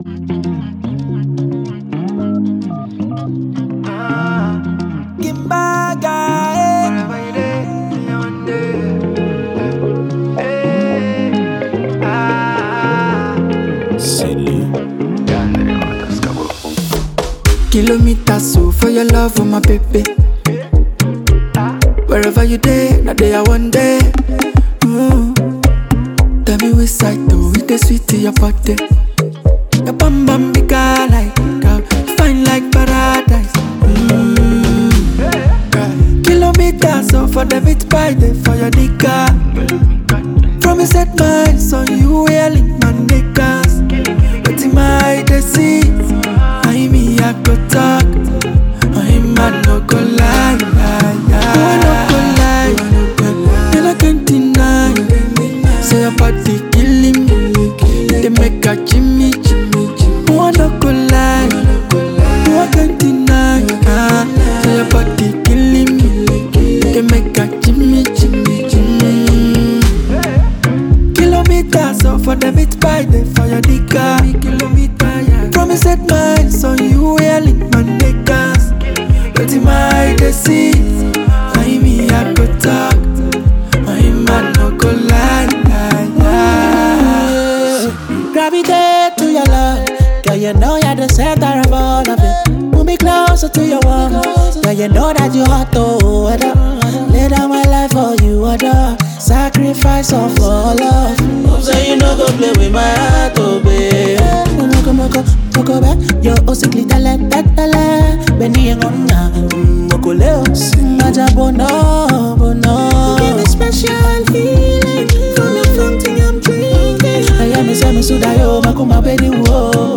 GH vocalist